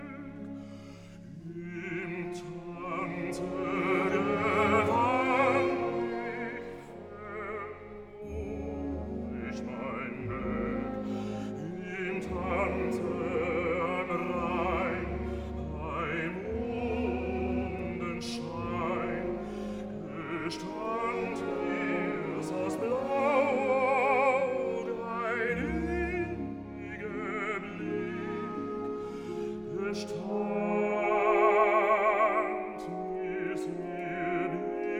Жанр: Классика
Classical